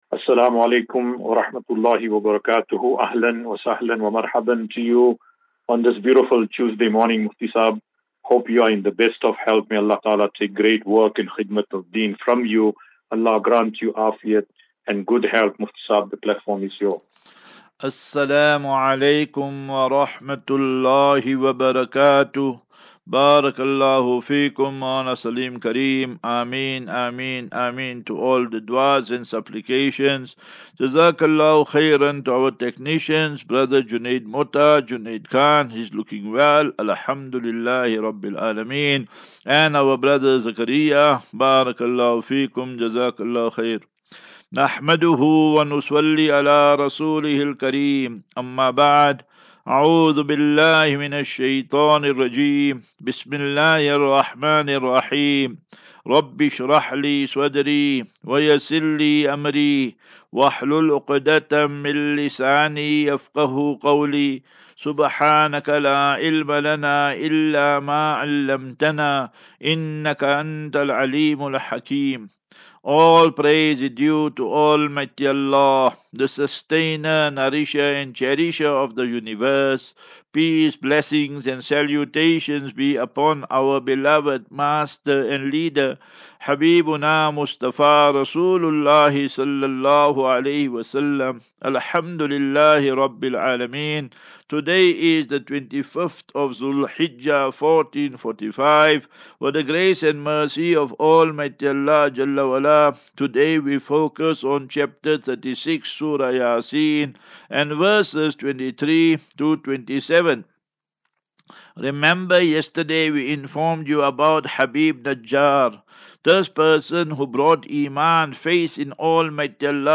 2 Jul 02 July 2024. Assafinatu - Illal - Jannah. QnA